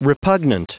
Prononciation du mot repugnant en anglais (fichier audio)